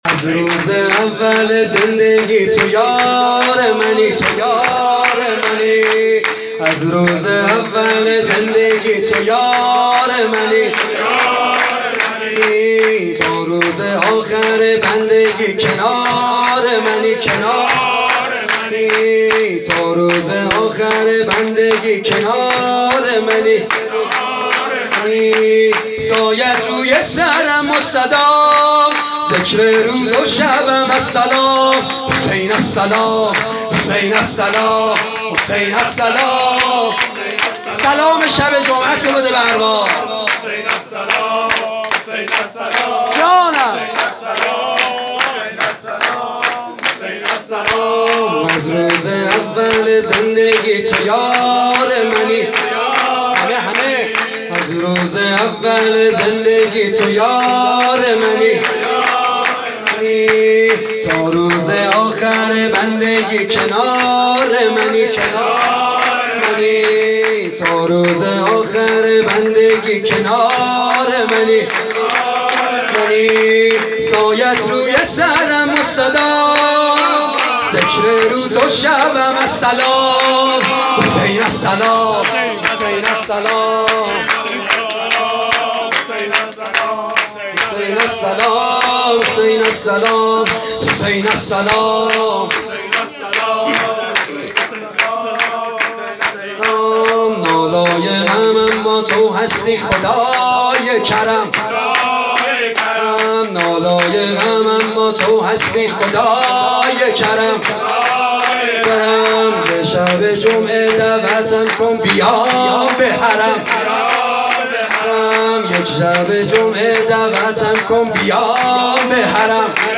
میلاد حضرت امام حسن عسکری_۹۸